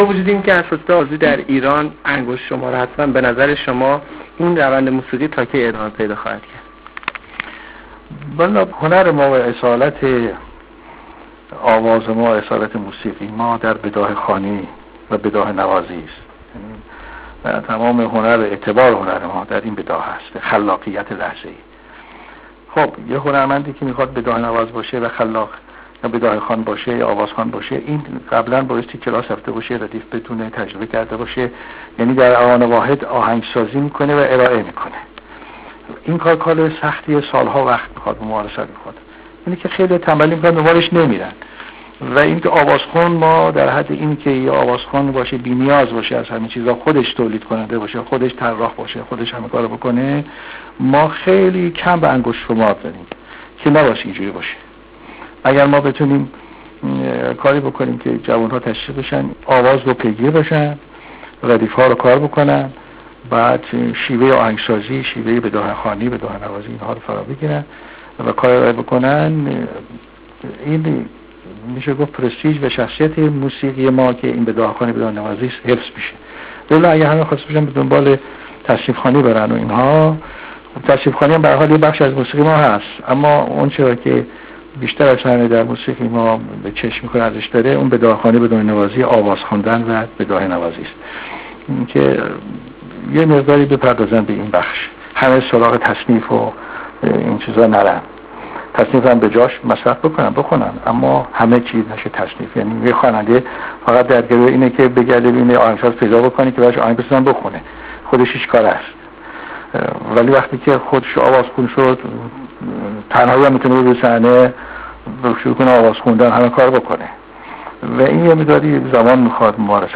SHAJARIAN-DAR-RADIO-TEHRAN.wav